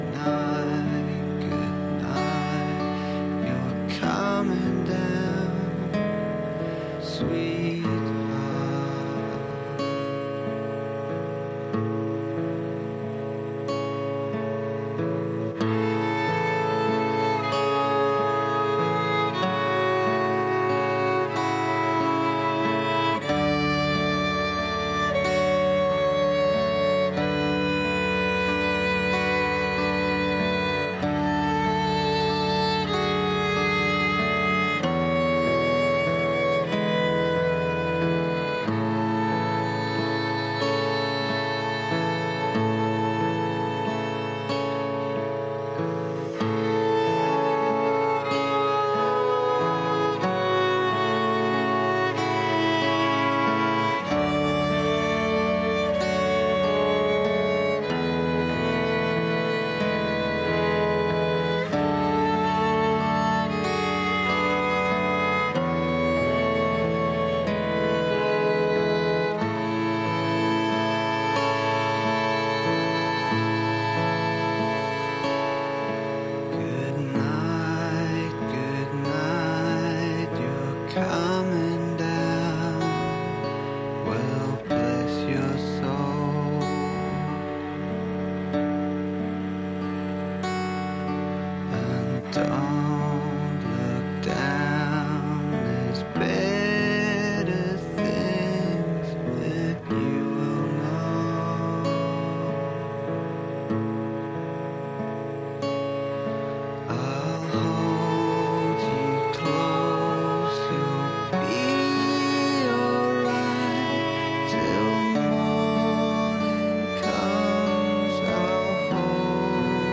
With this hour, I divided those 60-ish minutes between the ever-reliable Star Wars, the esoterically awesome one-and-only-ever-made Batman, and a 3-part radio drama given to me from someone who took a class with someone in Montreal.